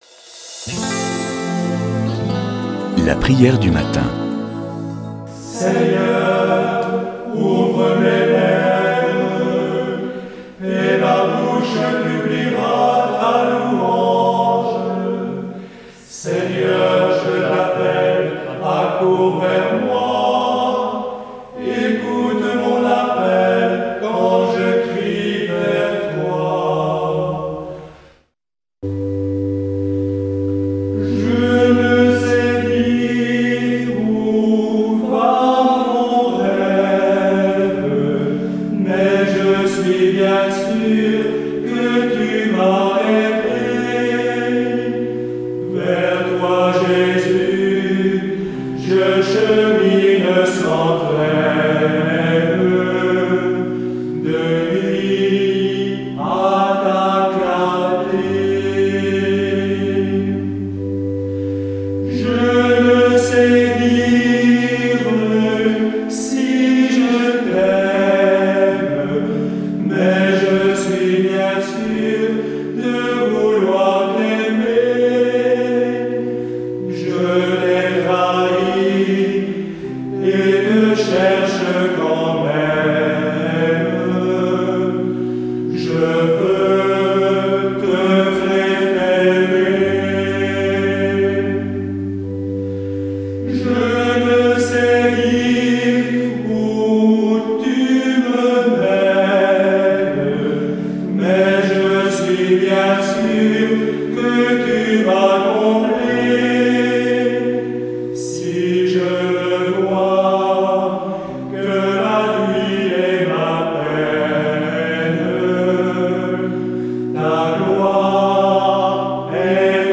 Prière du matin
STE THERESE D AVILA CARMEL D AVON